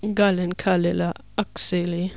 Aussprache Aussprache
gallenkallelaakseli.wav